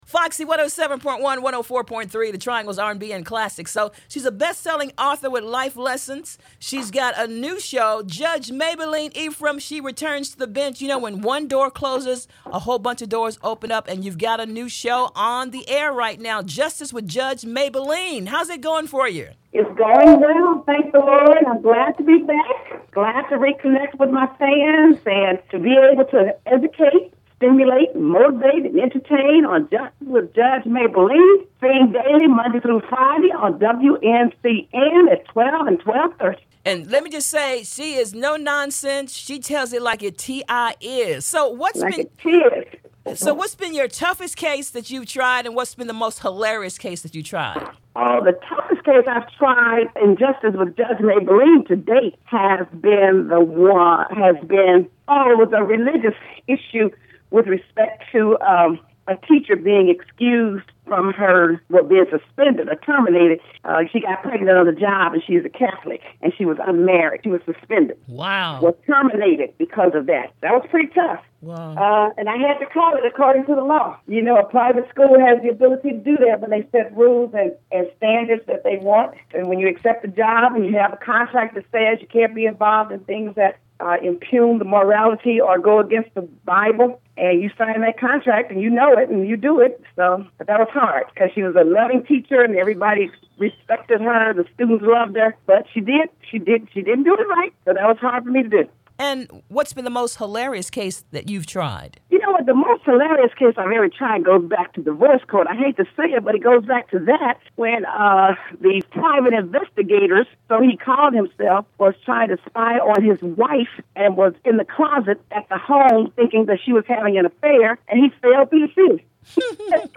Justice With Judge Mablean [Interview]